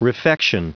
Prononciation du mot refection en anglais (fichier audio)
Prononciation du mot : refection